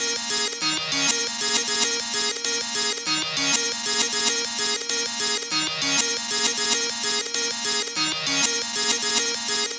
Rock Star - Reverse Synth Arpeggio.wav